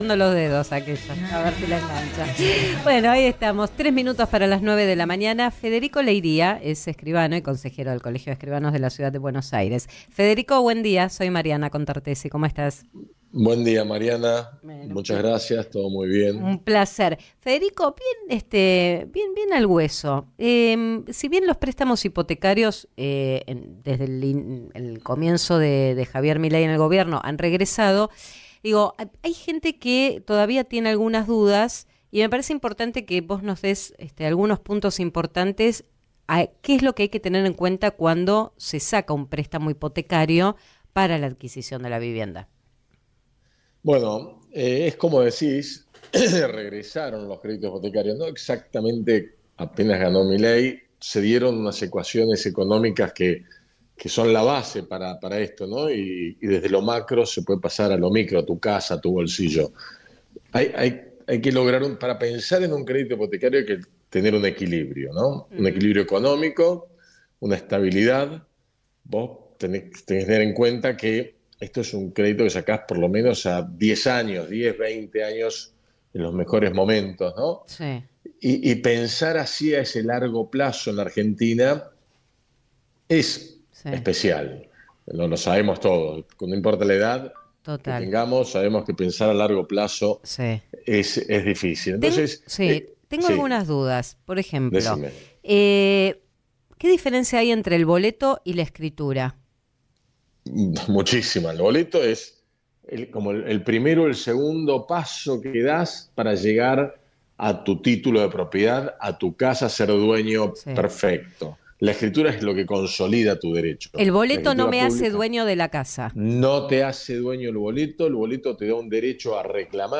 Columna en Radio La Red